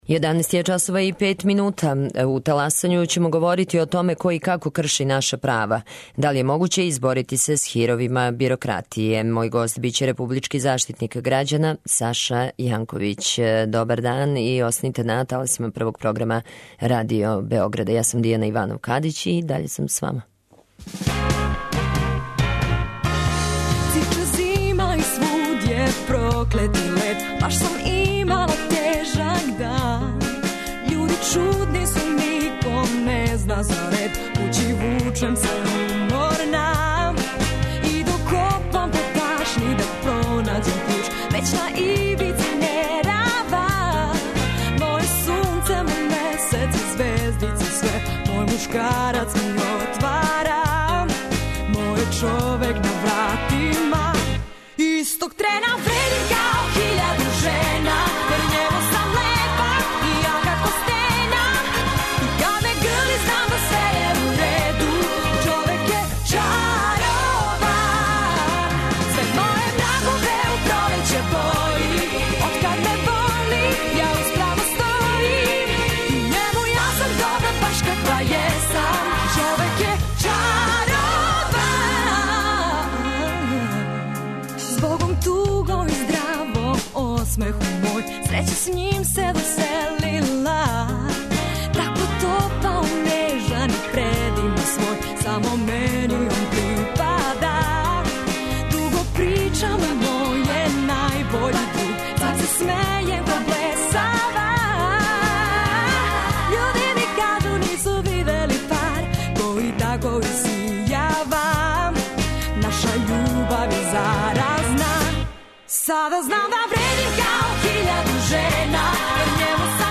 Гост Таласања - заштитник права грађана Саша Јанковић.